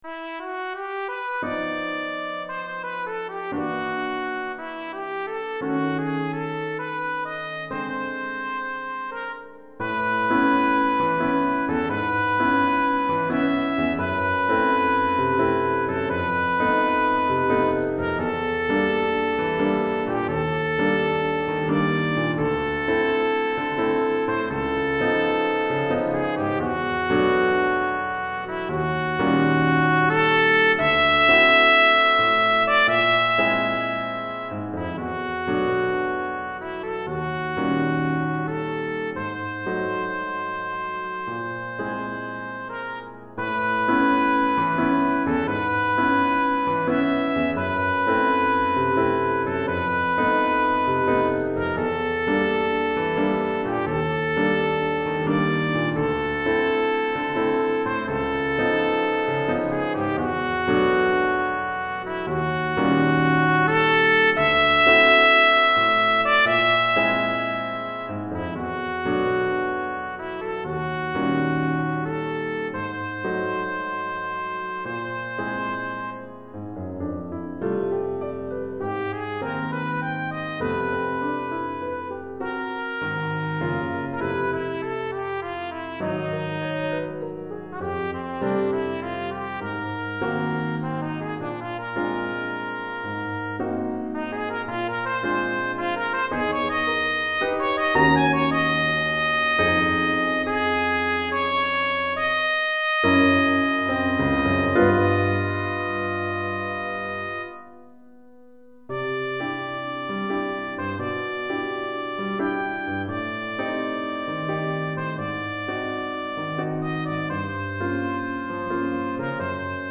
Trompette et Piano